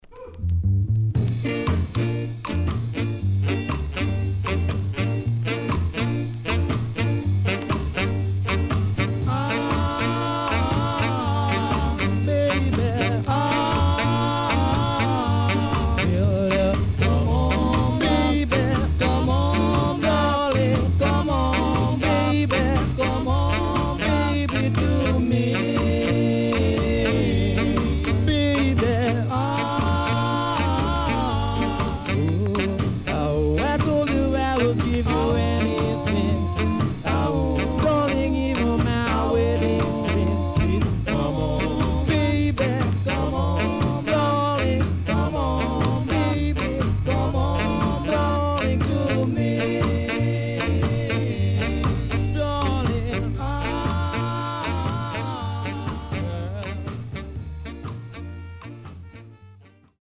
CONDITION SIDE A:VG(OK)〜VG+
SIDE A:プレス起因により少しチリノイズ入ります。